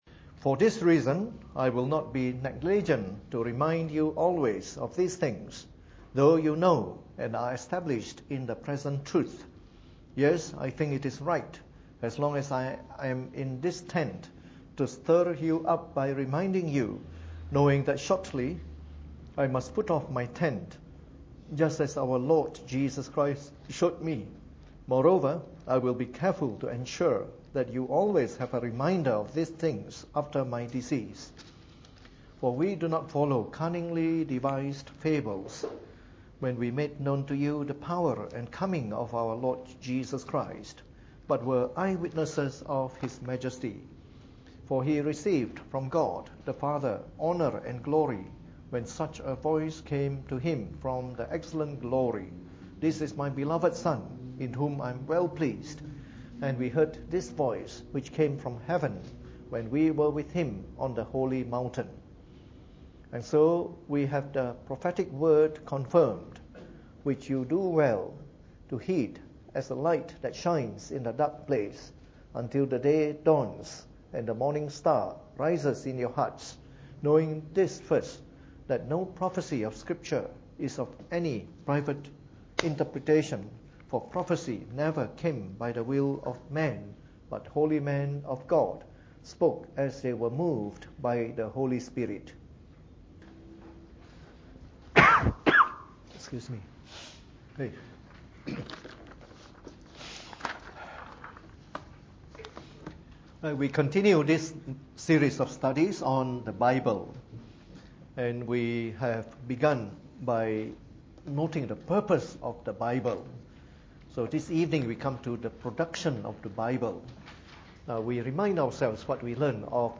Preached on the 25th of March 2015 during the Bible Study, from our new series of talks on Knowing the Bible.